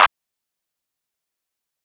REDD Clap (3).wav